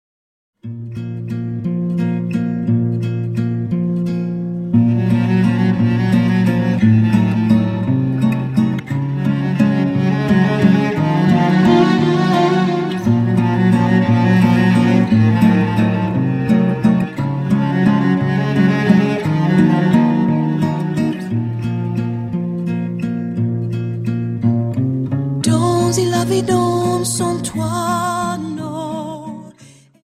Dance: Viennese Waltz 58